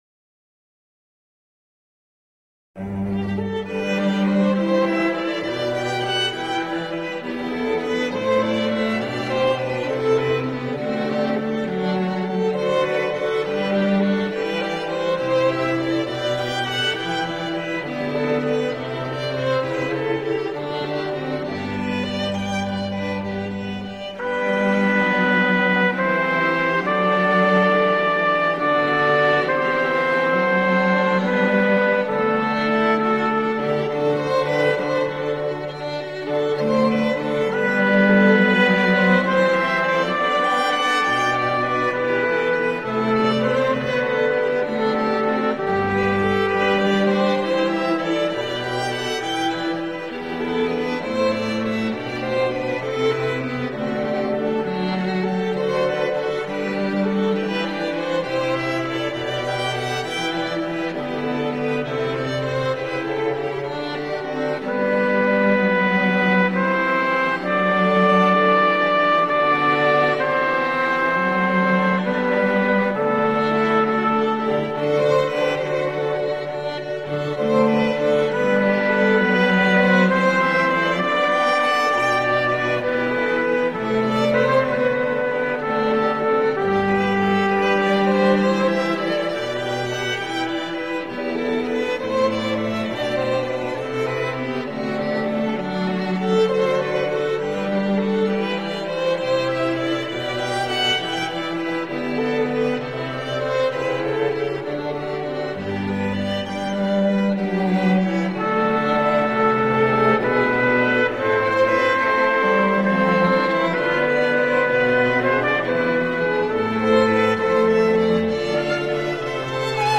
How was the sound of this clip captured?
After an opening meditation, we begin with a brief overview of "Breaking News" and also offer a segment for you to get your questions answered.